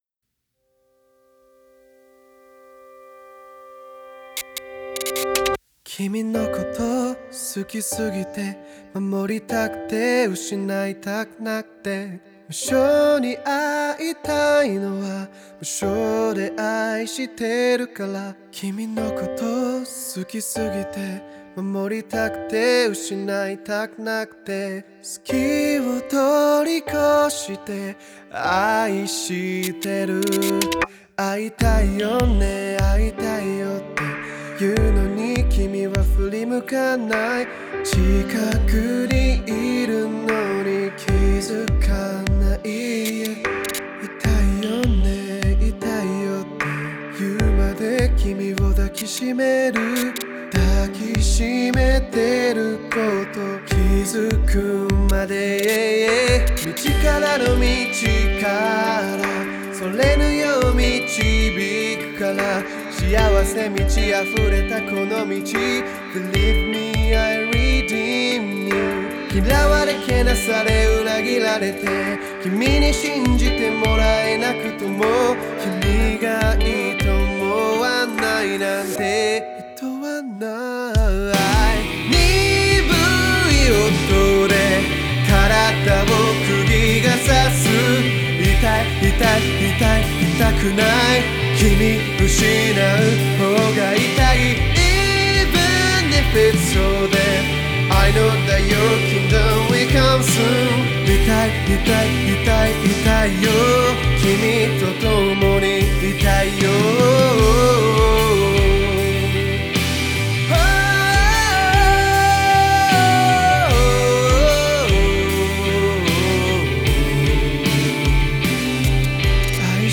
オリジナルKey：「G